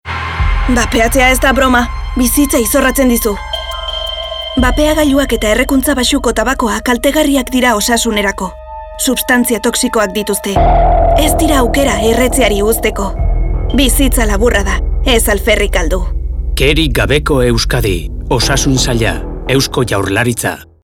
Irrati iragarkia